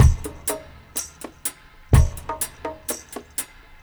62-FX+PERC1.wav